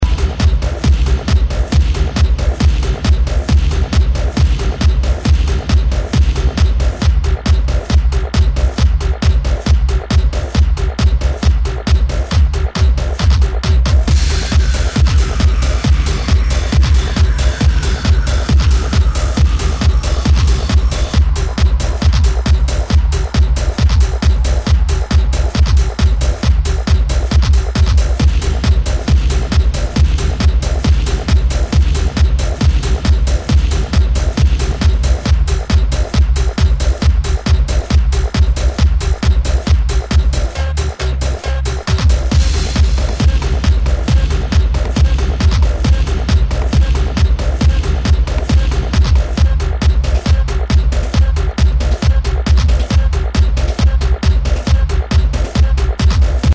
Styl: Techno, Minimal